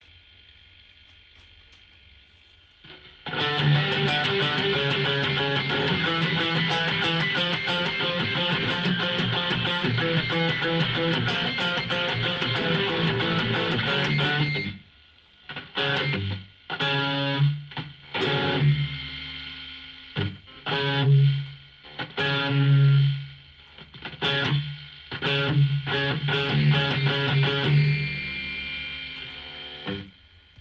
Und zwar tritt bei einem ihrer Songs ein fipsendes Geräusch auf (er vermutet die g und b saite). Es tritt wohl beim wechsel zwischen abgedämpft und unabgedämpften spiel auf...